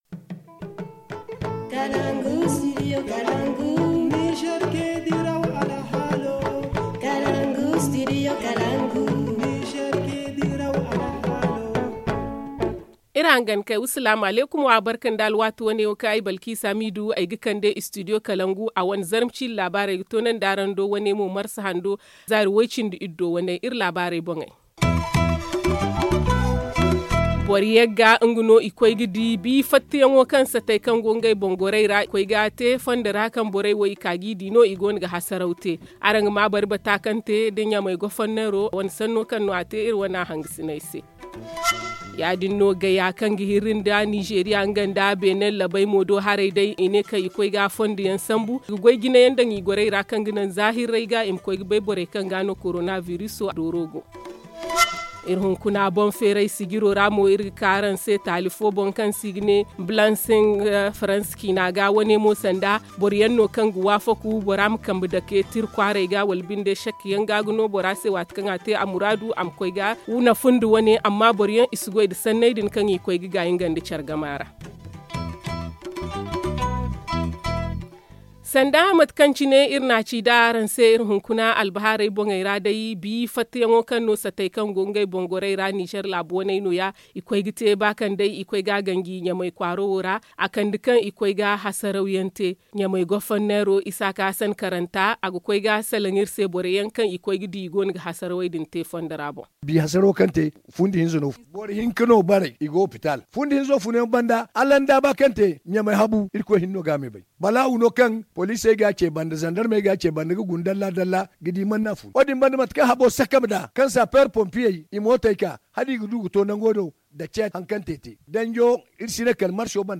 Le journal du 16 mars 2020 - Studio Kalangou - Au rythme du Niger